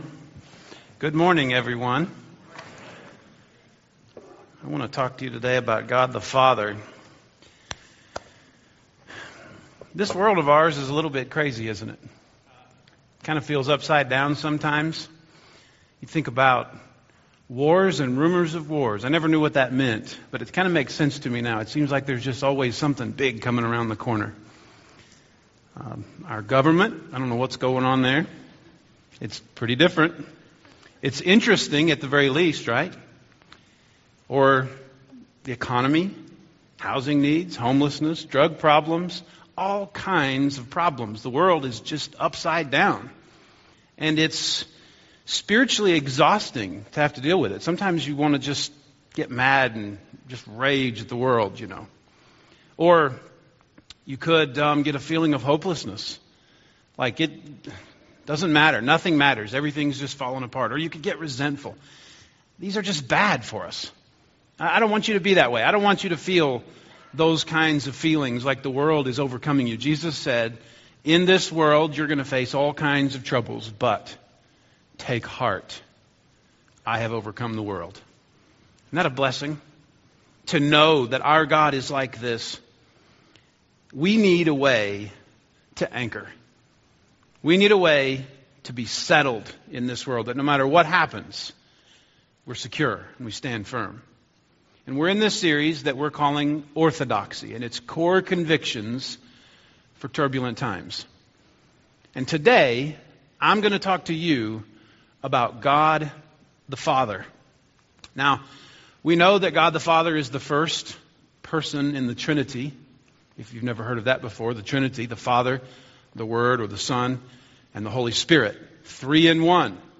Core Convictions for Turbulent Times Service Type: Sunday Morning « The Bible